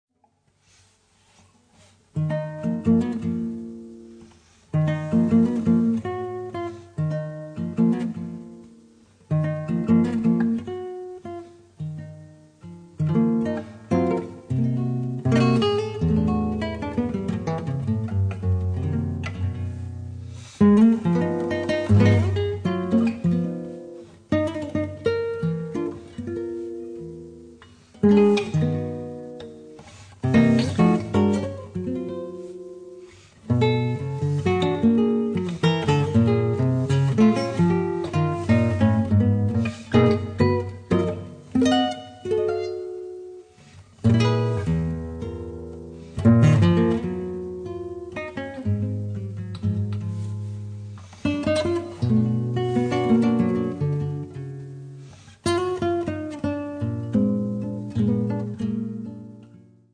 chitarra
contrabbasso
con le rugose corde che sottolineano il lirismo monkiano.